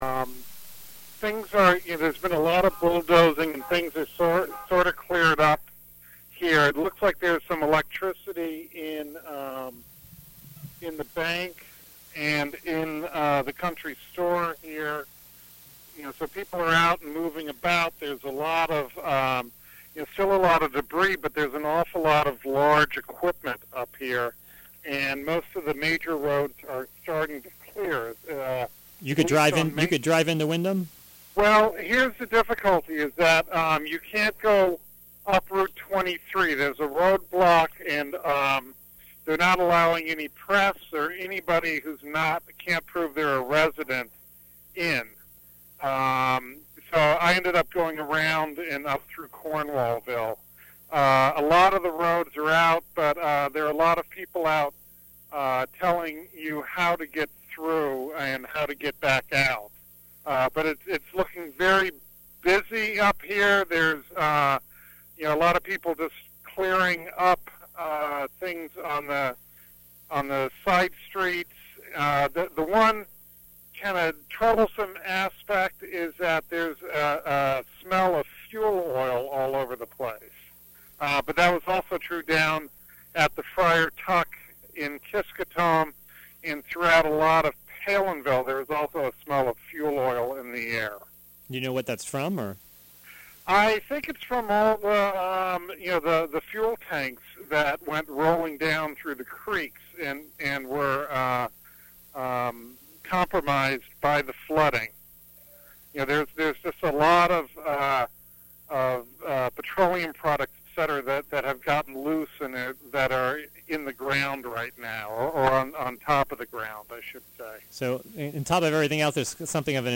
calling in live from Windham, about storm damage there.